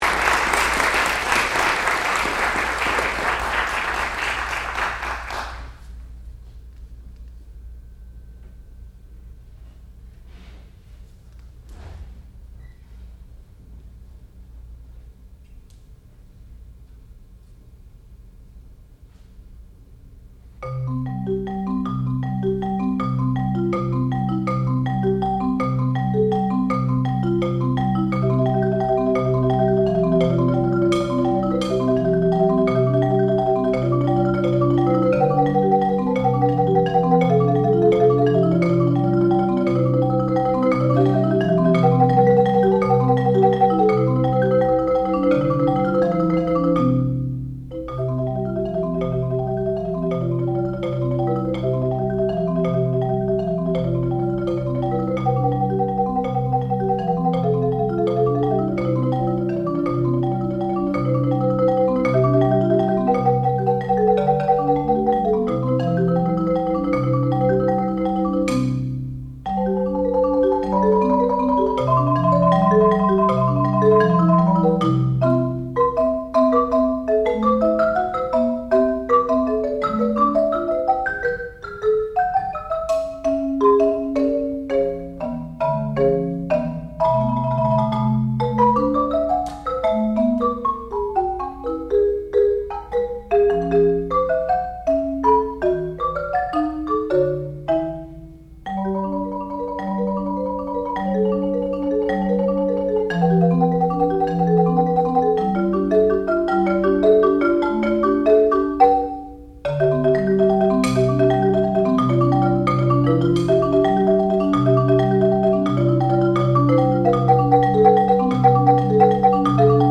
sound recording-musical
classical music
marimba
Master's Recital
percussion